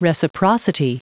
Vous êtes ici : Cours d'anglais > Outils | Audio/Vidéo > Lire un mot à haute voix > Lire le mot reciprocity
Prononciation du mot : reciprocity